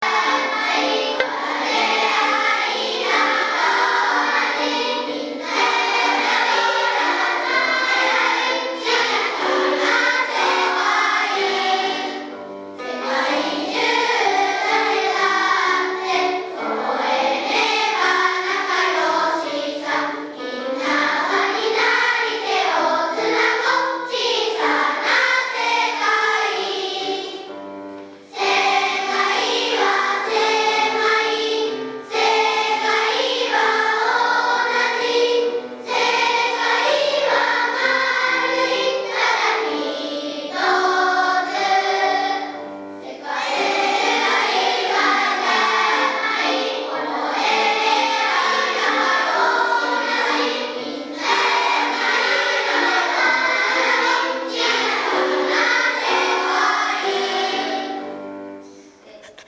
音楽集会は、４年生の発表でした。
別パートにつられないように、一所懸命歌いました。
４年生の発表①１.MP3